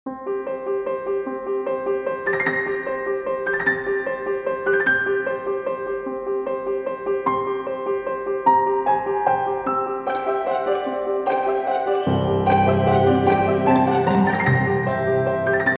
该作品音质清晰、流畅